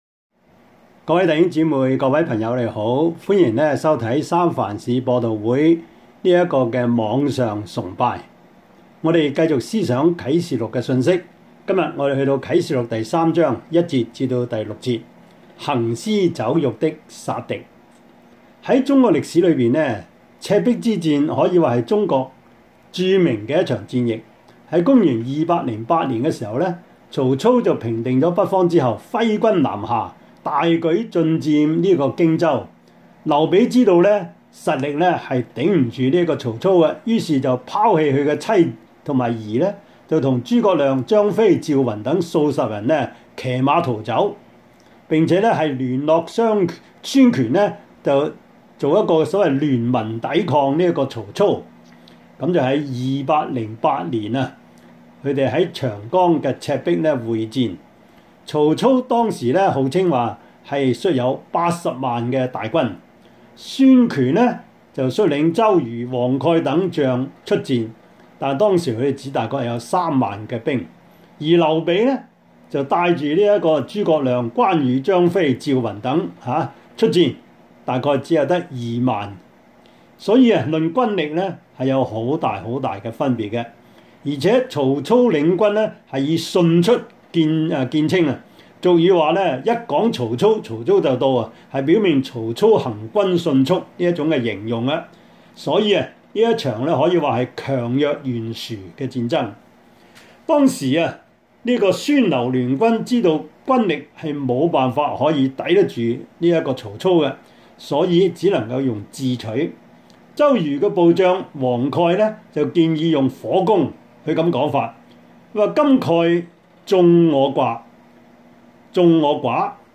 Service Type: 主日崇拜
Topics: 主日證道 « 門徒本色1 : 來跟從我 六七暴動之二 »